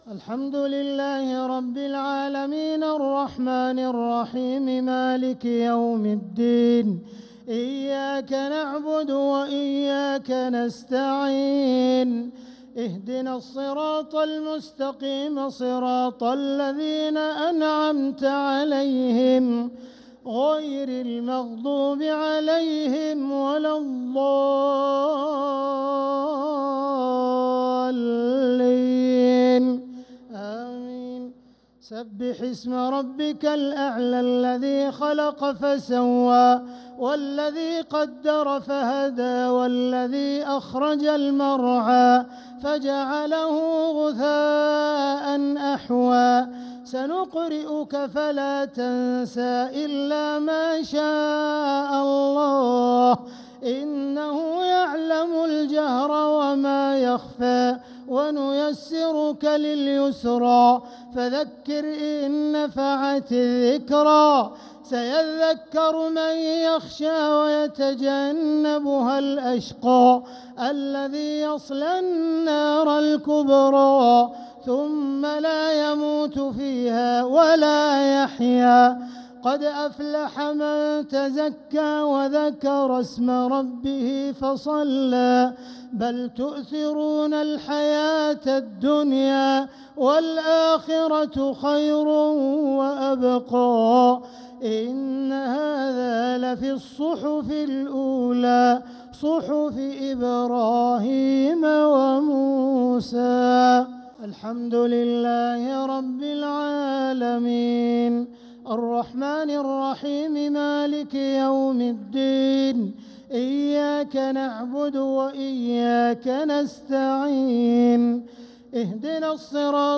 صلاة الشفع و الوتر ليلة 4 رمضان 1446هـ | Witr 4th night Ramadan 1446H > تراويح الحرم المكي عام 1446 🕋 > التراويح - تلاوات الحرمين